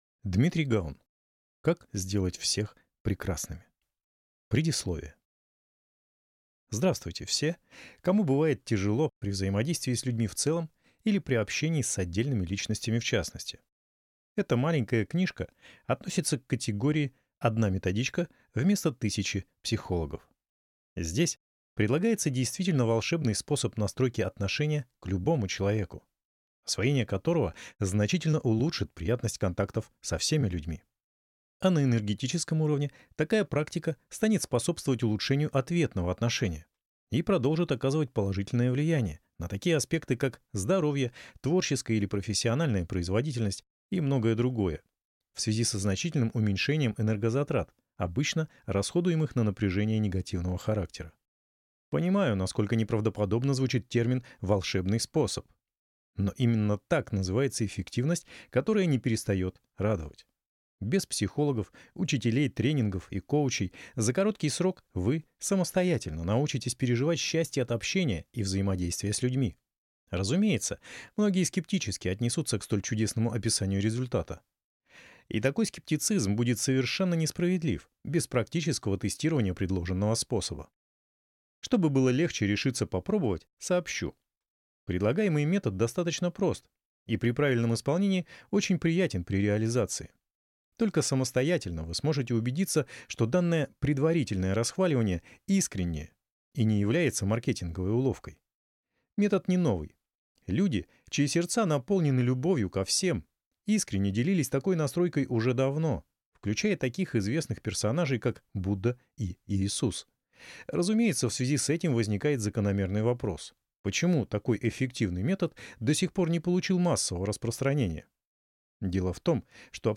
Аудиокнига Как сделать всех – прекрасными | Библиотека аудиокниг